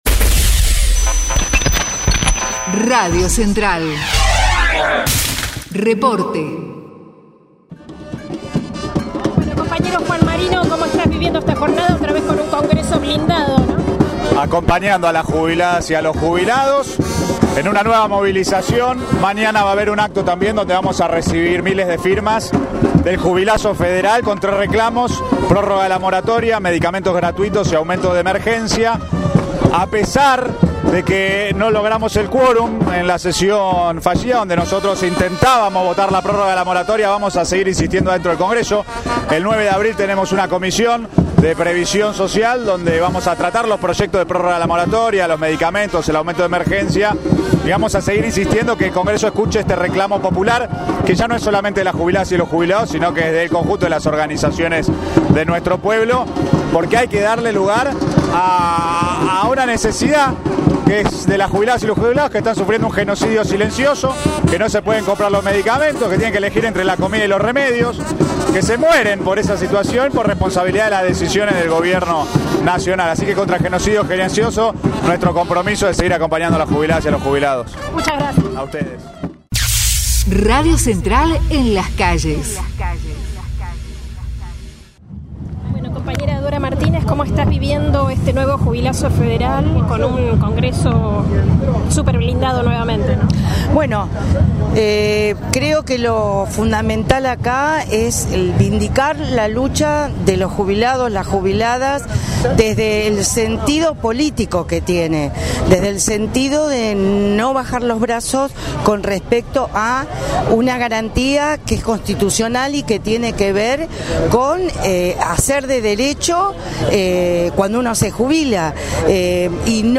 CTA JUNTO AL JUBILAZO FEDERAL - Testimonios en Plaza Congreso